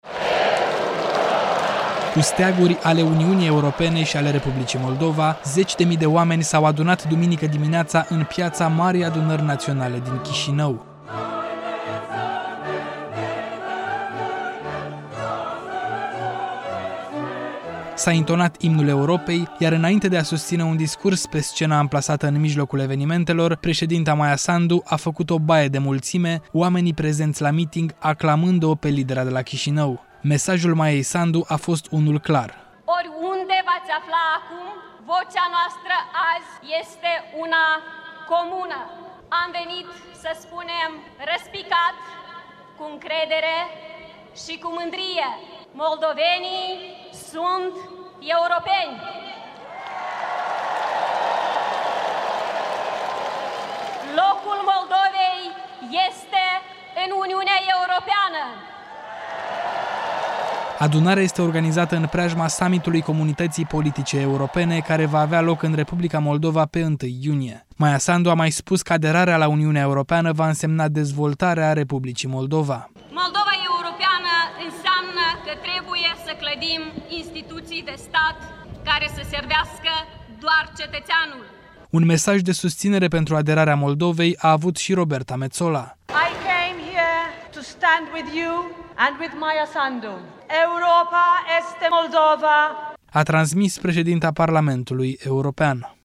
Cu steaguri ale Uniunii Europene și ale Republicii Moldova, zeci de mii de oameni s-au adunat duminică dimineață în Piața Marii Adunări Naționale.
Un mesaj de susținere pentru aderarea Moldovei la Uniunea Europeană a avut și Roberta Metsola, care a rostit un scurt mesaj în limba română.